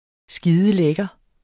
Udtale [ ˈsgiːðəˈlεgʌ ]